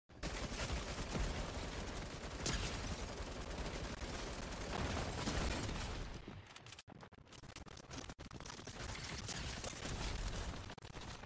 OG Fortnite - Multiple Panic Shots, Survived Though